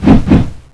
midslash1.wav